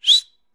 PFIFF      1.wav